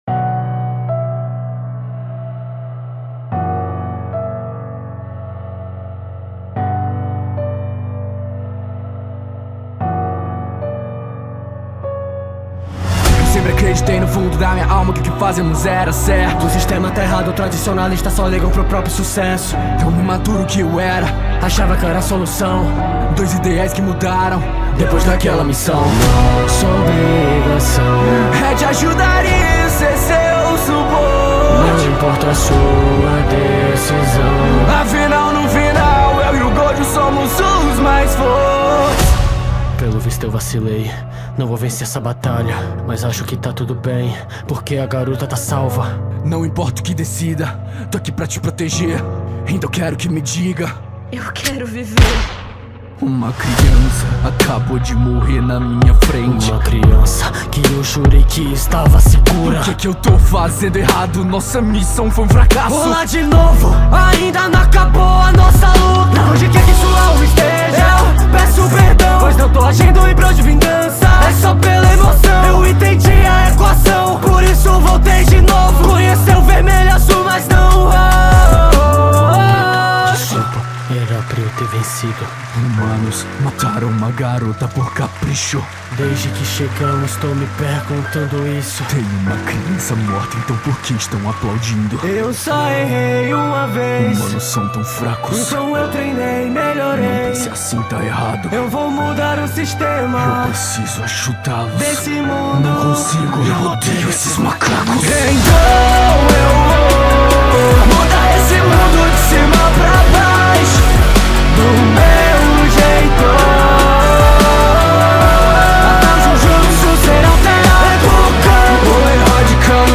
2025-02-23 14:51:03 Gênero: Rap Views